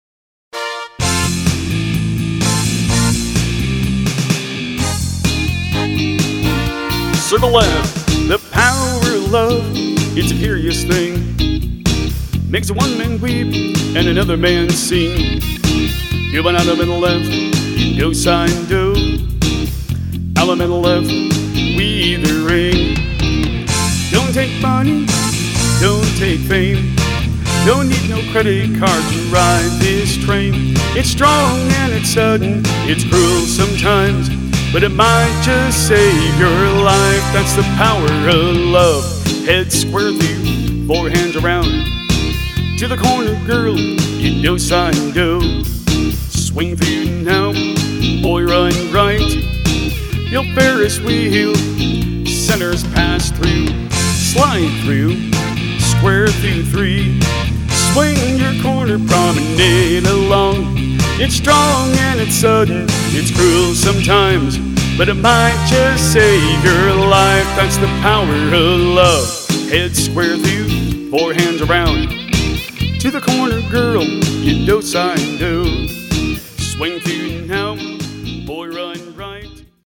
Mainstream
you will get 2 versions of the instrumental.